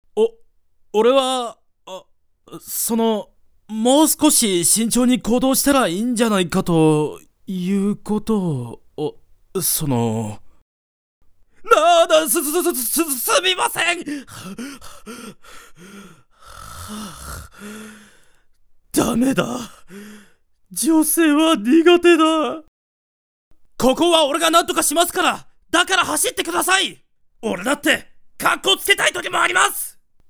演じていただきました！
性別：男
大きな体躯に似合わず、内気で弱気な虎の獣人青年。